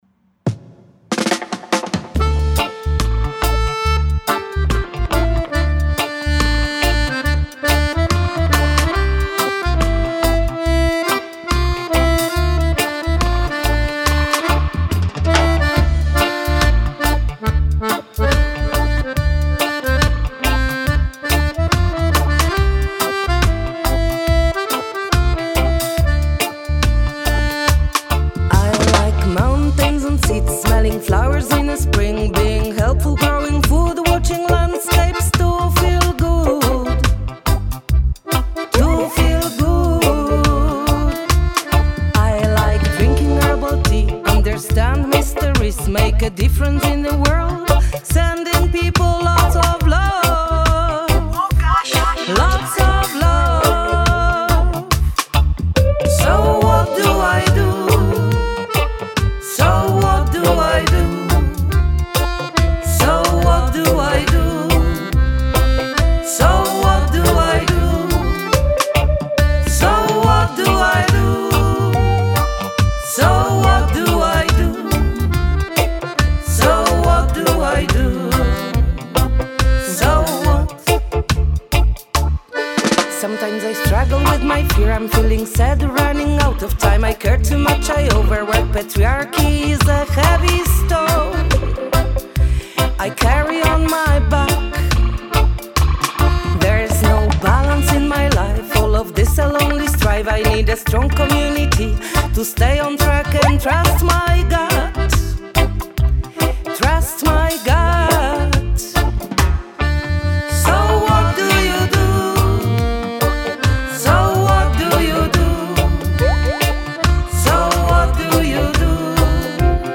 During this exchange they also created a song together which has become our SWIFT anthem.